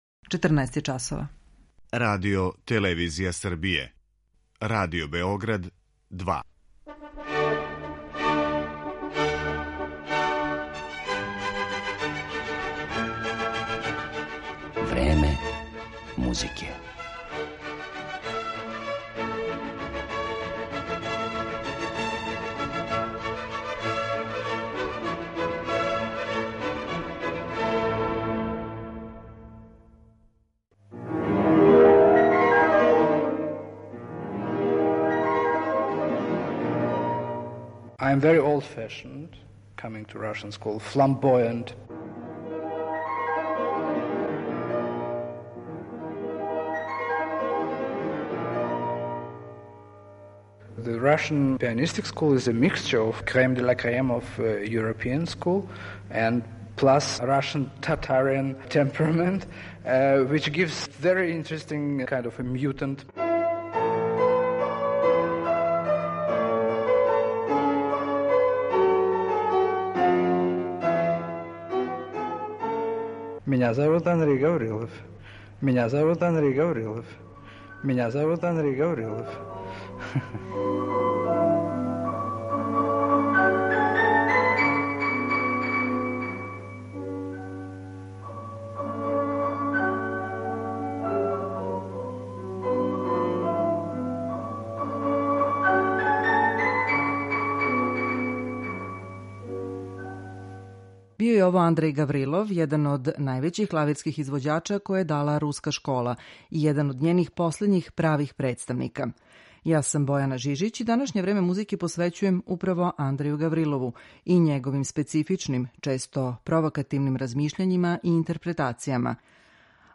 Данашња емисија посвећена је овом провокативном руском пијанисти кога представљамо и кроз ексклузивни интервју.